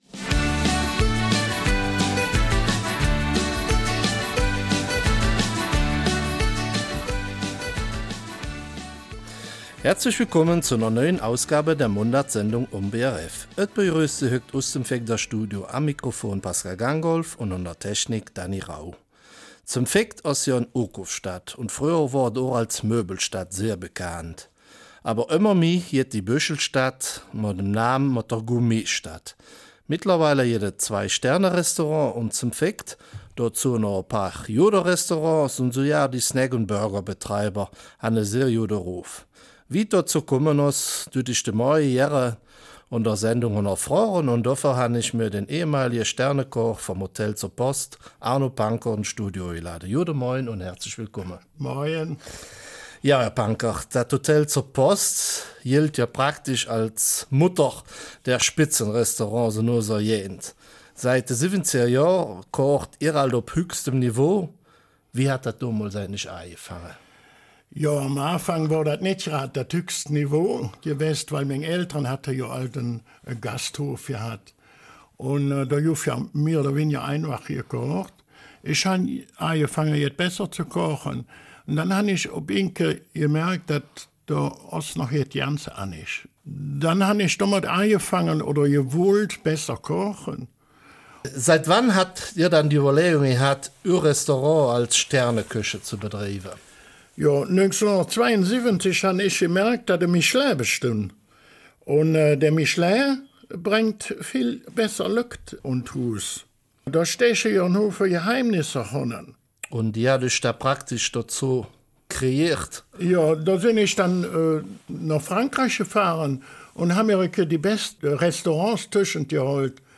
Eifeler Mundart: Die Gourmetstadt St. Vith